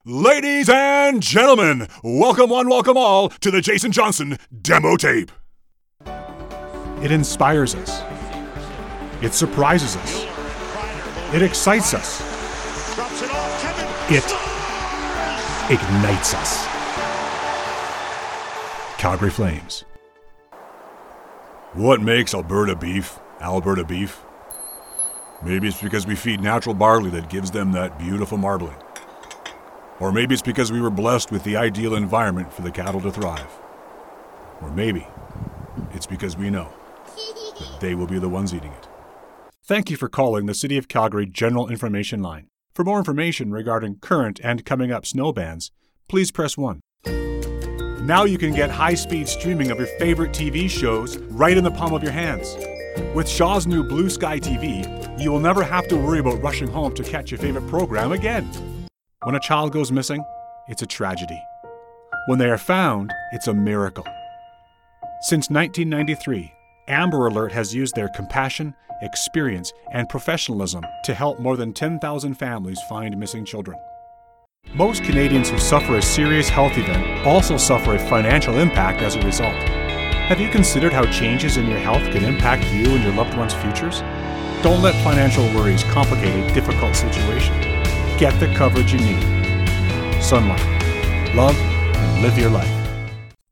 voice over demo reel